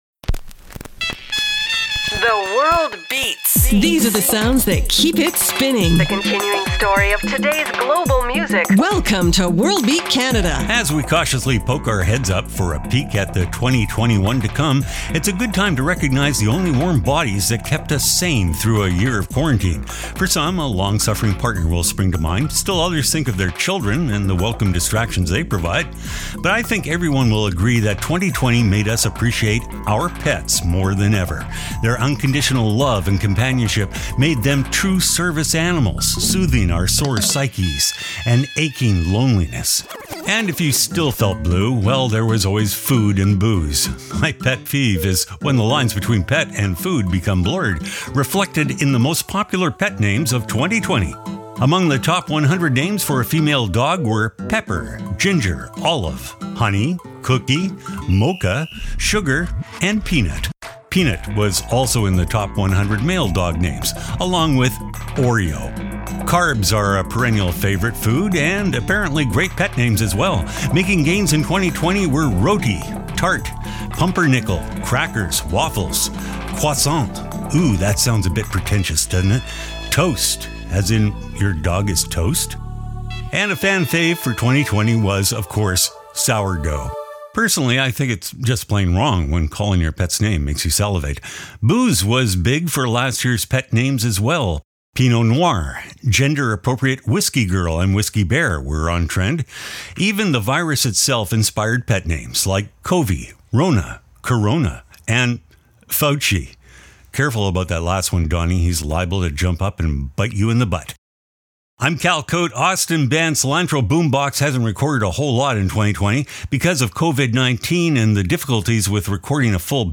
exciting global music alternative to jukebox radio